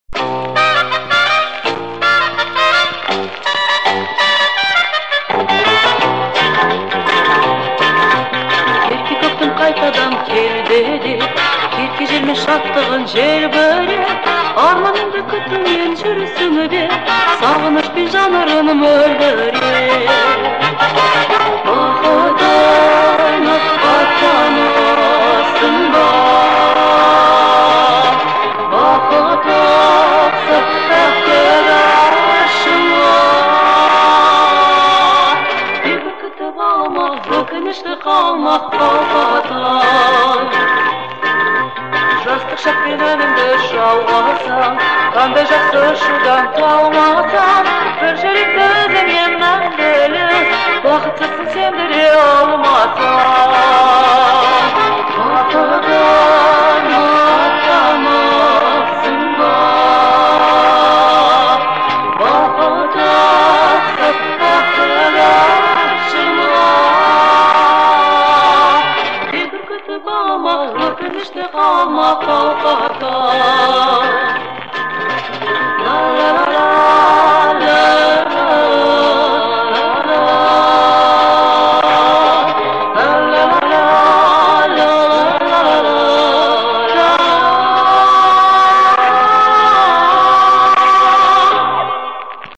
Запись 70-х.